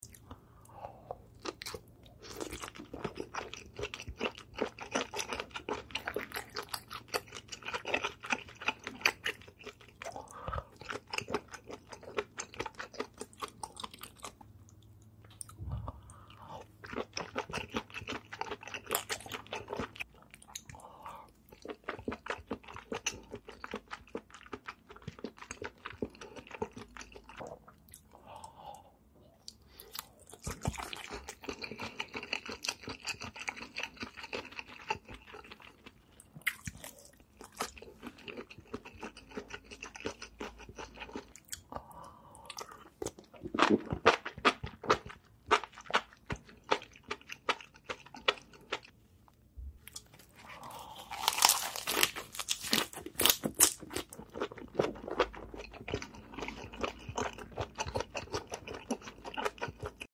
Delicious SPICY SEAFOOD MUKBANG 🥵🤤🐙